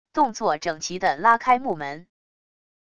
动作整齐的拉开木门wav音频